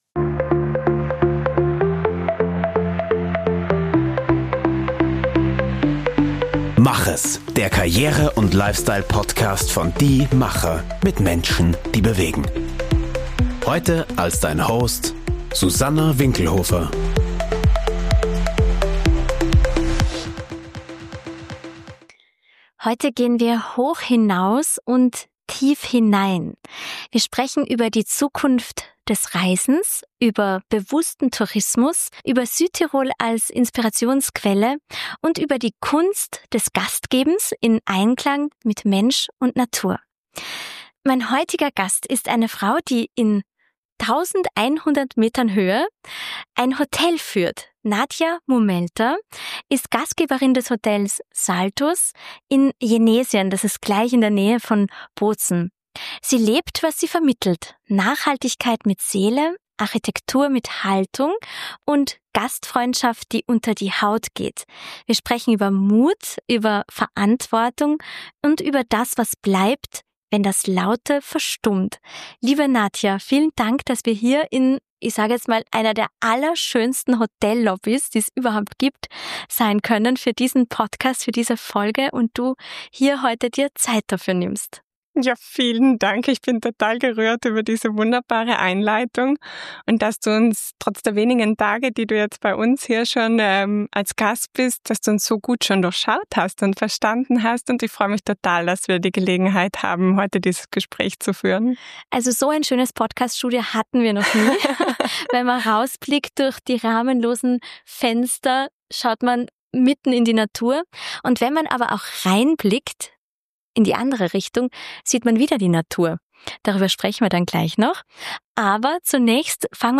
In dieser Folge erfährst du: - Wie Nachhaltigkeit im Hotelalltag wirklich gelebt wird - Warum der Wald ihr wichtigster Lehrer ist - Wie drei Frauen ein Familienerbe neu interpretieren - Was Reisen im Jahr 2040 mit Achtsamkeit zu tun hat Ein Gespräch über Haltung, Herkunft, und die Kunst, als Gastgeberin Wurzeln zu schlagen, ohne stehen zu bleiben.